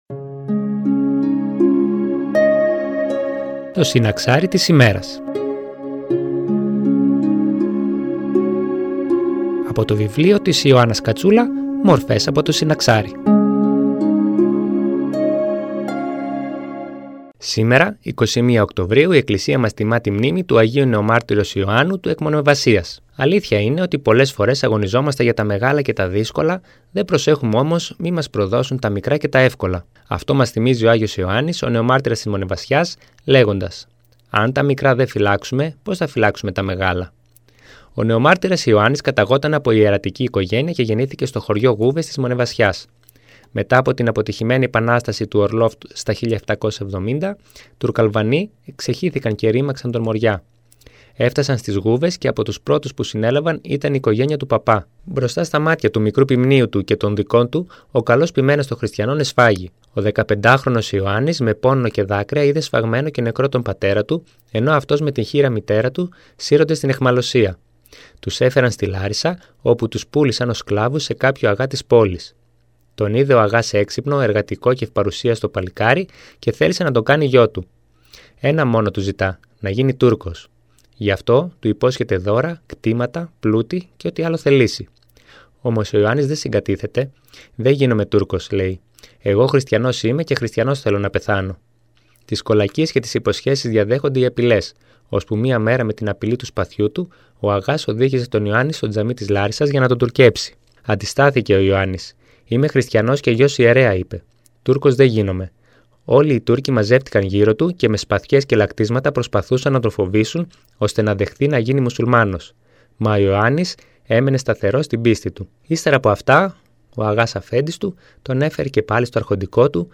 Μια ένθετη εκπομπή που μεταδίδεται από Δευτέρα έως Παρασκευή στις 09:25 από την ΕΡΤ Φλώρινας.
Εκκλησιαστική εκπομπή